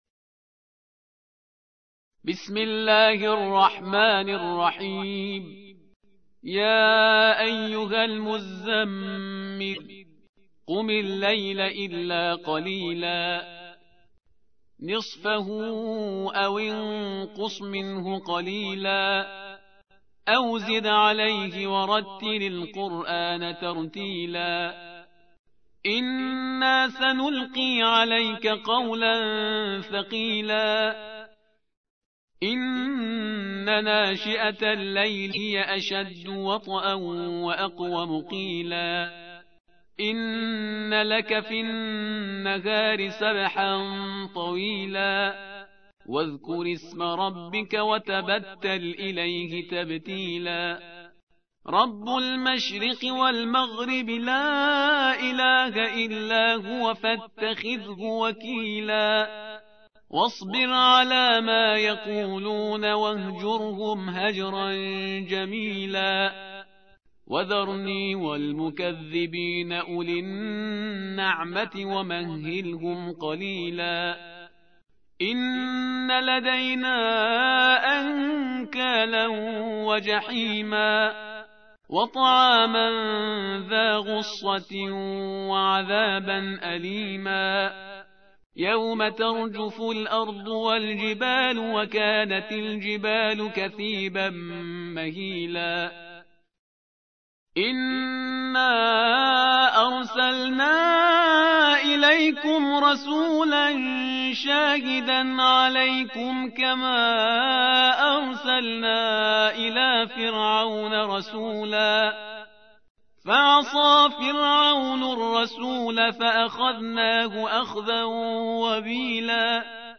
القرآن الكريم